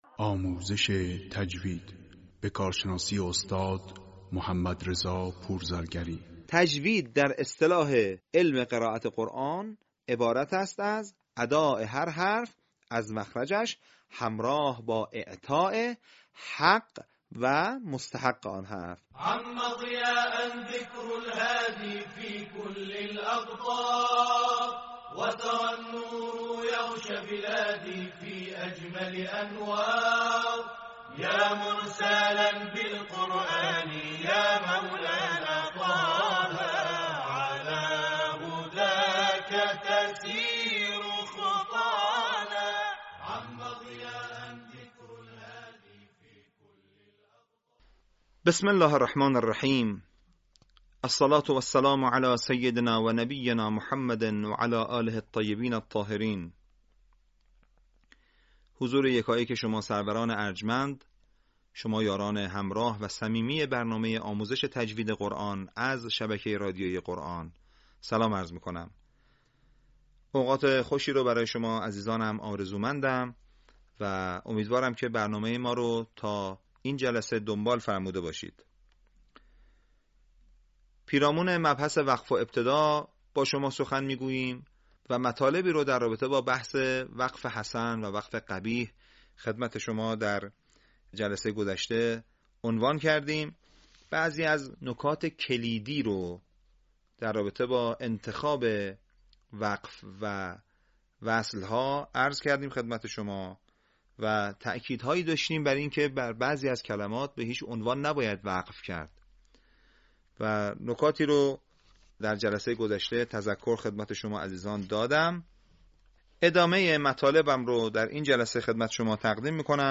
آموزش تجویدی قرآن کریم